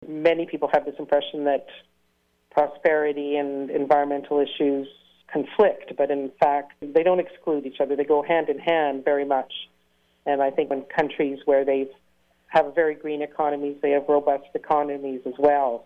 What you will be hearing is audio from an interview recorded May 22nd of 2022.